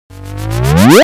cartoon43.mp3